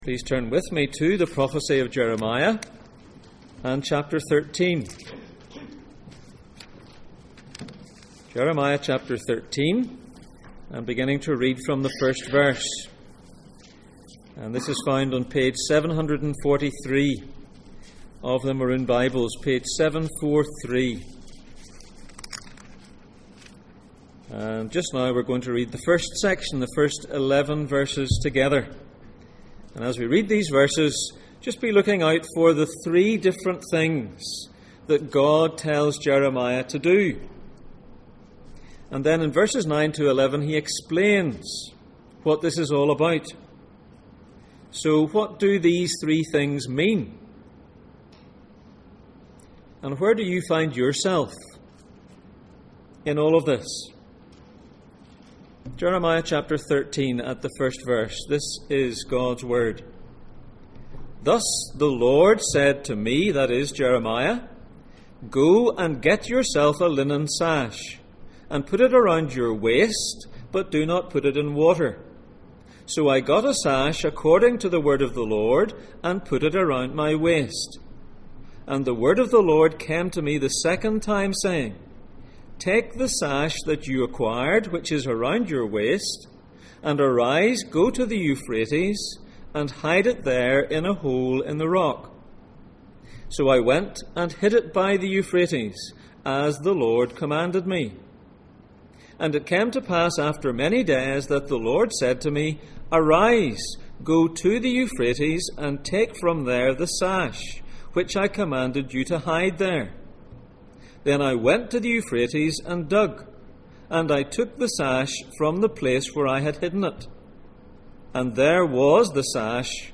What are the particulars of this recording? Passage: Jeremiah 13:1-11 Service Type: Sunday Morning